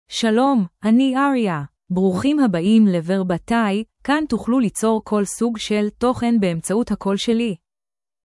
AriaFemale Hebrew AI voice
Aria is a female AI voice for Hebrew (Israel).
Voice sample
Listen to Aria's female Hebrew voice.
Female
Aria delivers clear pronunciation with authentic Israel Hebrew intonation, making your content sound professionally produced.